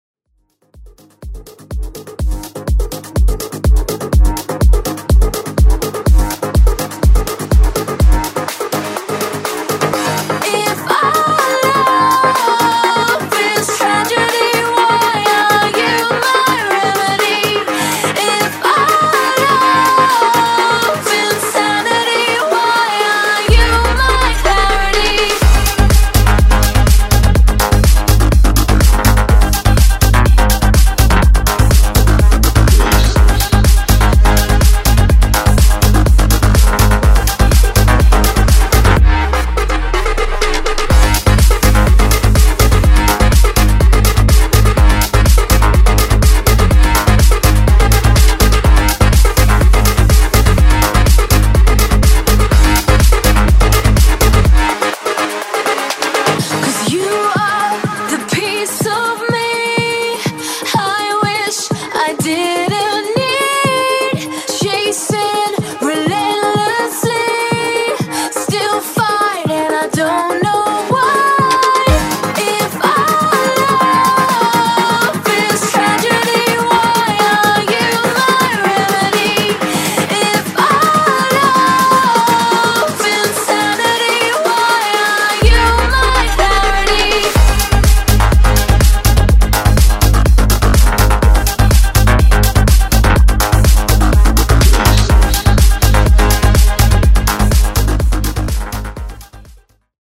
BASS HOUSE , MASHUPS , TOP40 Version: Clean BPM: 124 Time